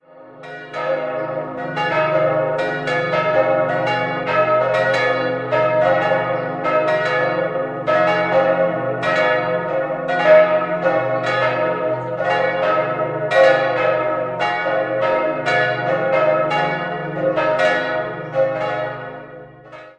Idealquartett c'-es'-f'-as' Die Glocken 1, 2 und 4 wurden 1949 von Benjamin Grüninger (Villingen) in Weißbronze gegossen. Glocke 3 dürfte noch aus dem Vorgängergeläut von 1922 stammen.